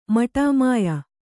♪ maṭāmāya